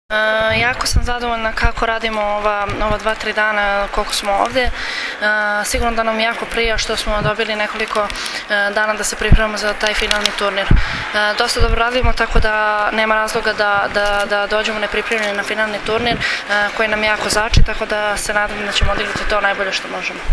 IZJAVA ANE BJELICE, KOREKTORA SRBIJE